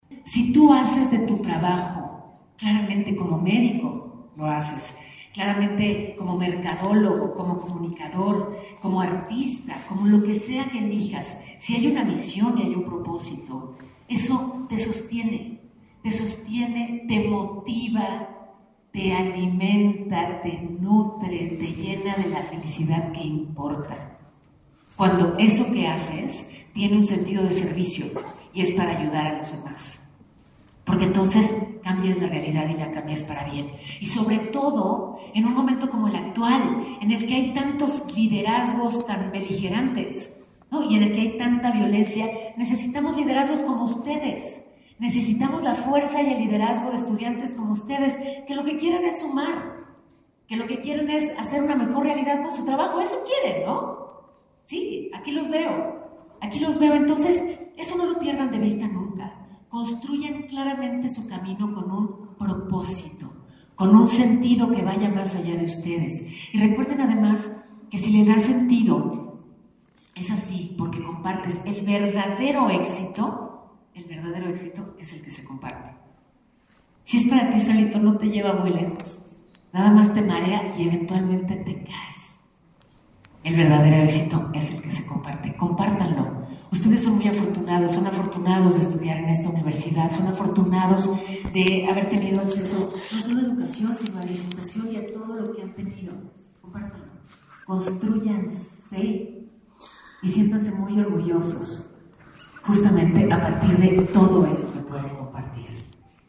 La periodista Paola Rojas impartió en la BUAP la conferencia “Juntas somos más fuertes” en el teatro del Complejo Cultural Universitario.